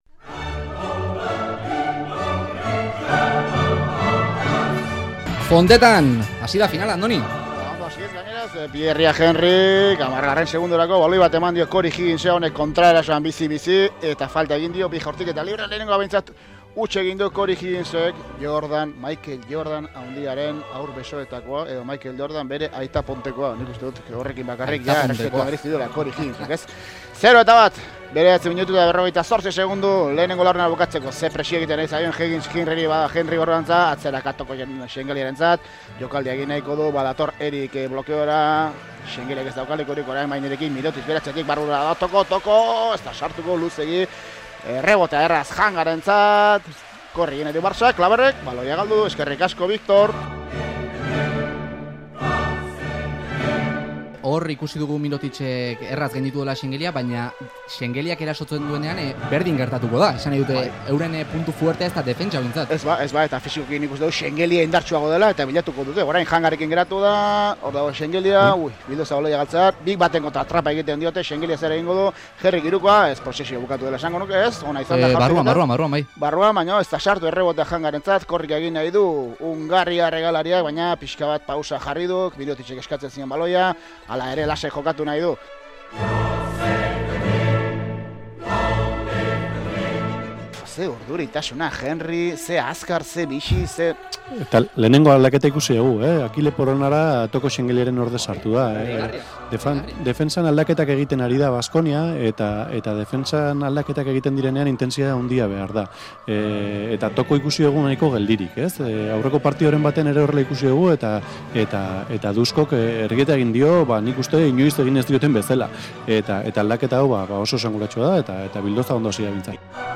Audioa: Baskoniak laugarren aldiz irabazi du ACB titulua. Euskadi Irratiko emankizun osoa zortzi minututan laburtu dugu.